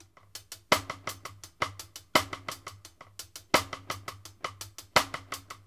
Index of /90_sSampleCDs/Sampleheads - New York City Drumworks VOL-1/Partition F/SP REGGAE 84
JUST HANDS-R.wav